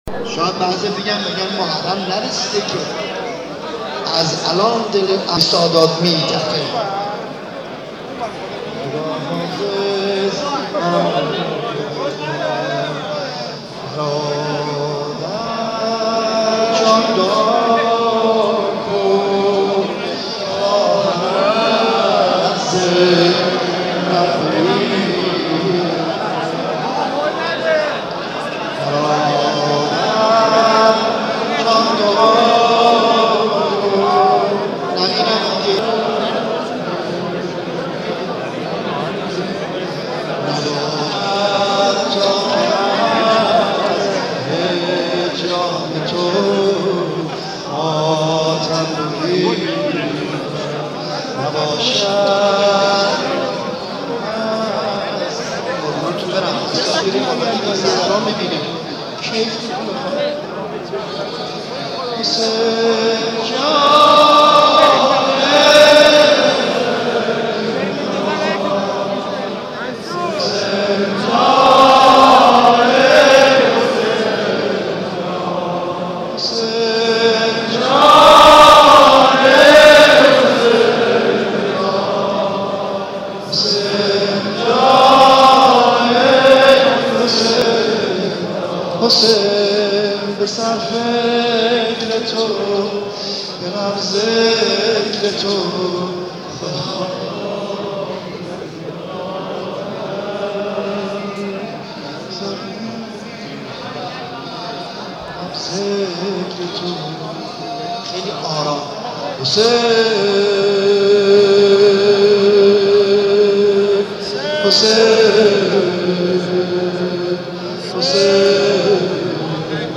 مراسم سومین شب عزاداری ایام شهادت حضرت مسلم بن عقیل با حضور خیل عزاداران در حرم حضرت عبدالعظیم حسنی برگزار شد.